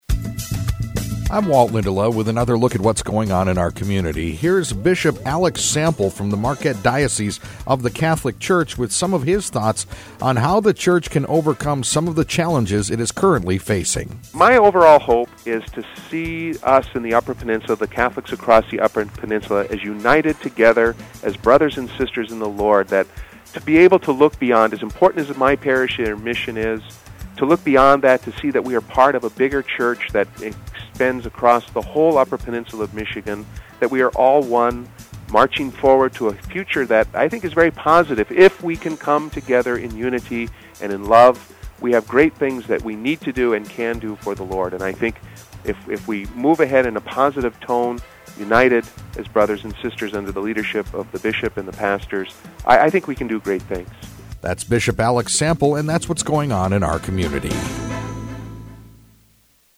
INTERVIEW: Bishop Alex Sample, Marquette Catholic Diocese